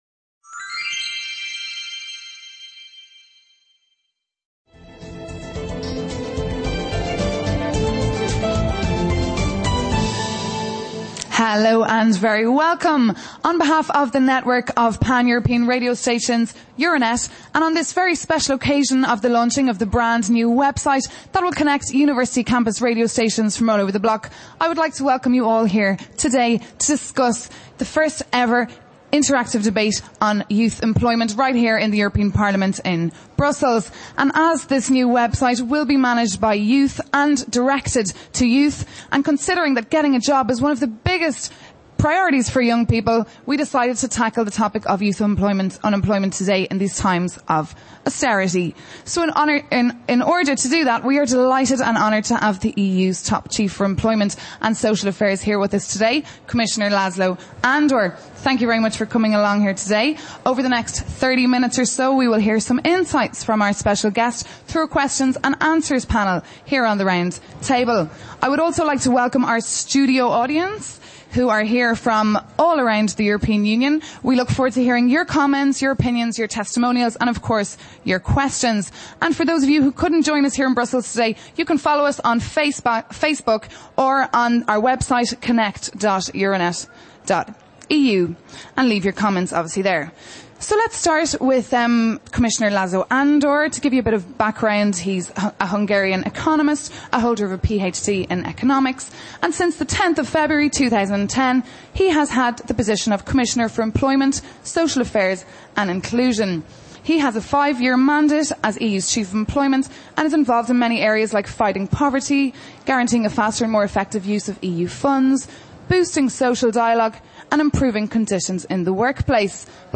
Entrevista de estudiantes a László Andor /…
Live Debate about Youth Unemploym
Reunion, debate, coloquio...